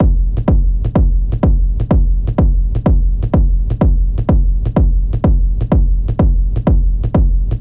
刺耳音效
描述：刺耳音效。
标签： 重采样 神经 运动 杜比 低音的 变压器
声道立体声